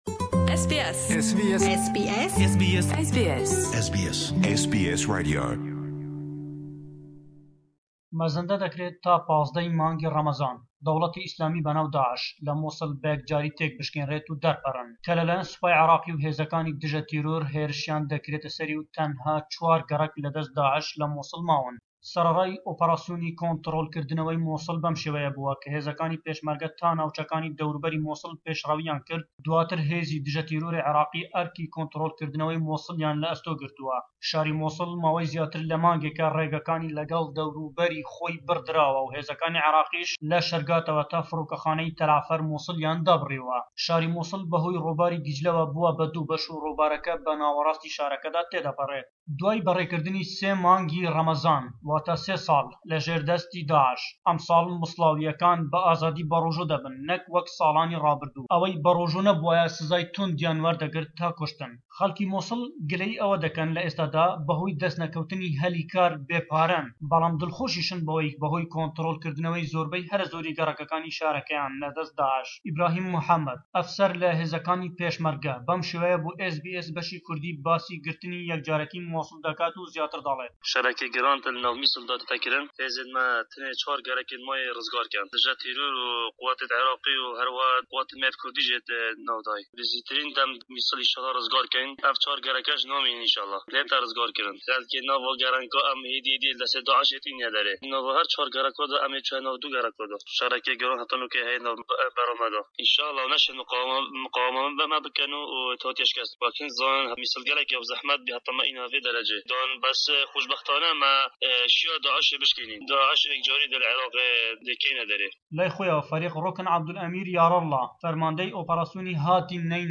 Raportêkîi Taybetî